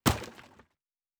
pgs/Assets/Audio/Fantasy Interface Sounds/Wood 11.wav at master
Wood 11.wav